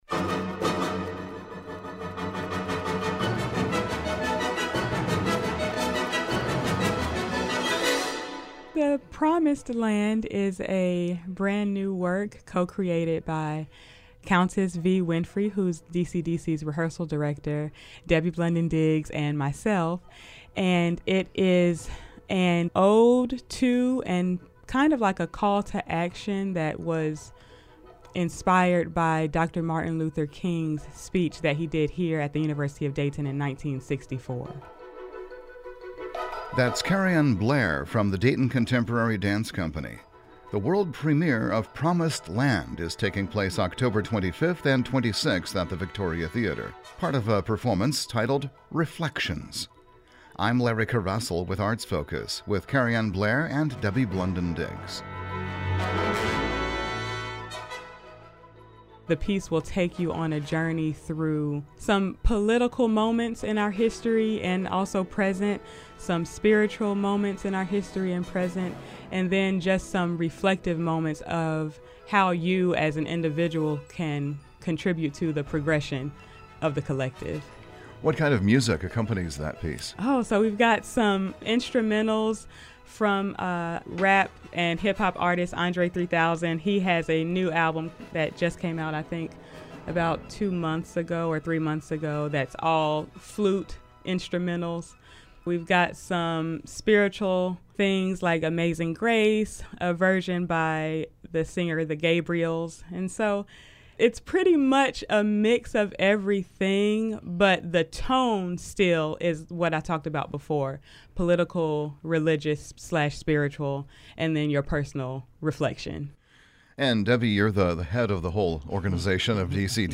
Each segment features a guest from the regional arts community discussing current activities, such as concerts, exhibitions and festivals.